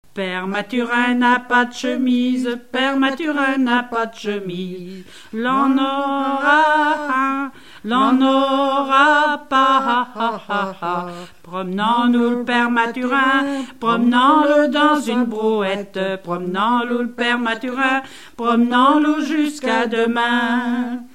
en duo
Genre énumérative
Pièce musicale inédite